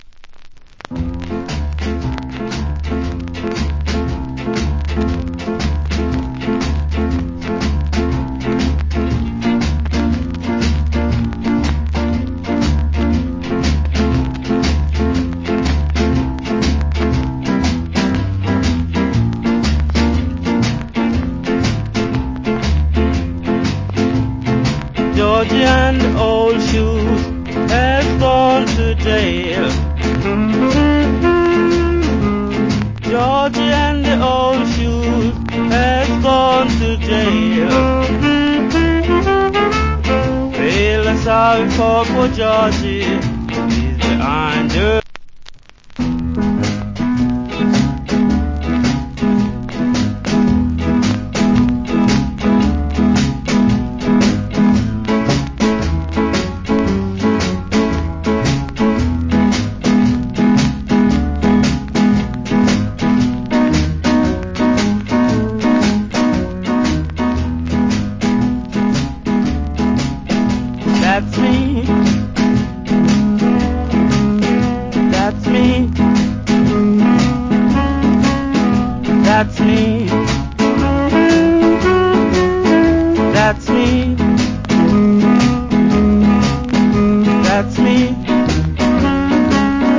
Wicked JA R&B Vocal.